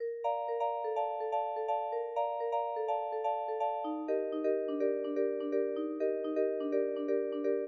IBI Bell Riff Eb-Bb-A-D-G-B.wav